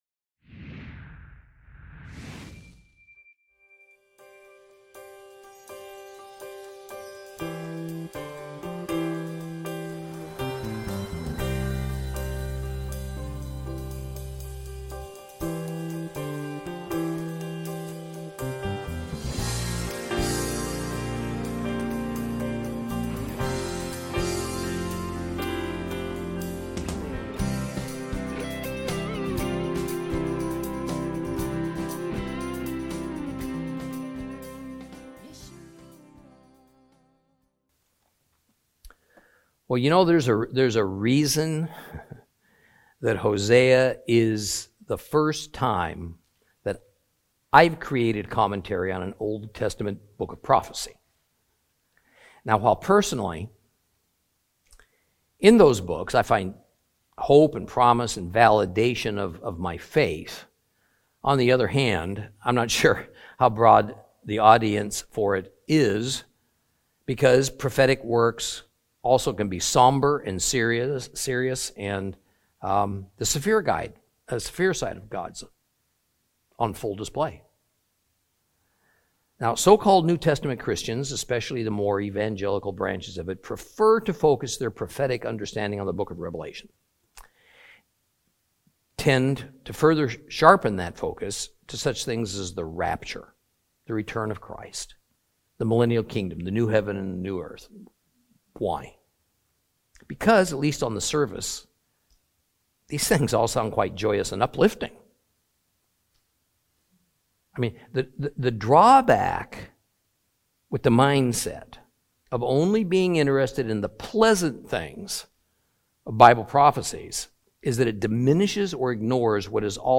Teaching from the book of Hosea, Lesson 12 Chapter 7.